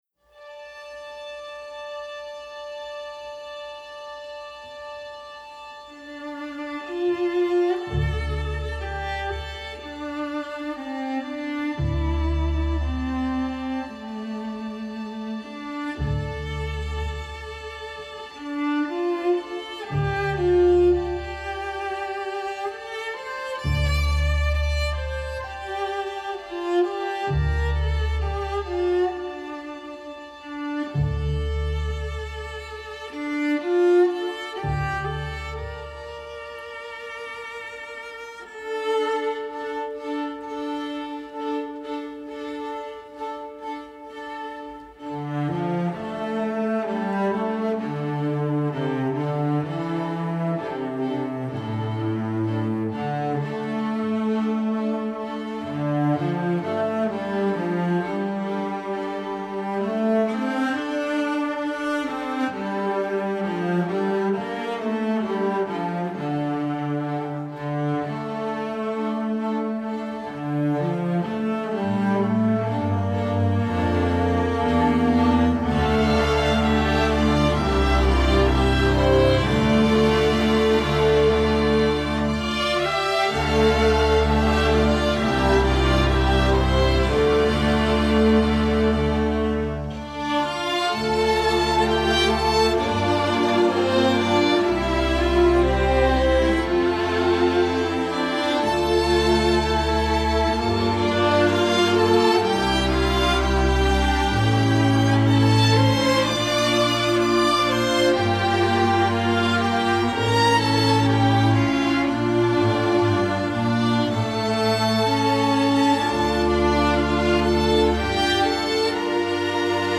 Composer: traditional American
Voicing: String Orchestra